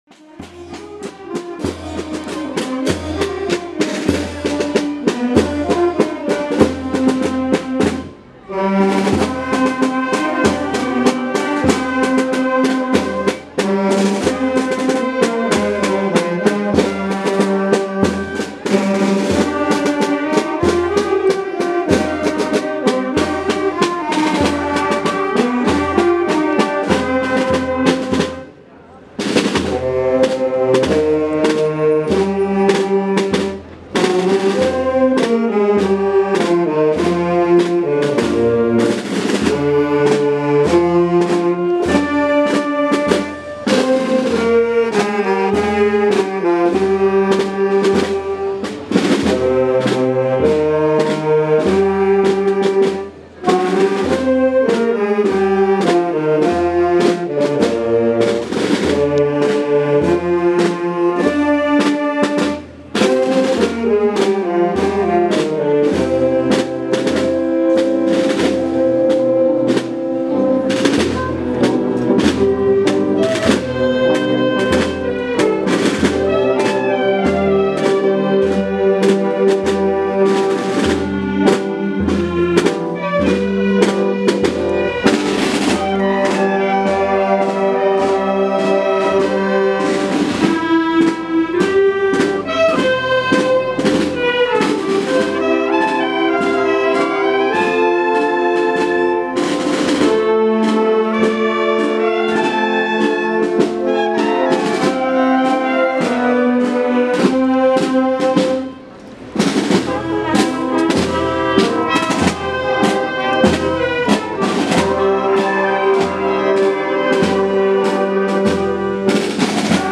Procesión Santa Eulalia 2014
La imagen salió de la Iglesia de Santiago pasadas las cinco de la tarde arropada por varios cientos de personas que demostraron el fervor y la admiración que profesan hacia la patrona.
La Banda de Música de la Agrupación Musical de Totana cerró la comitiva.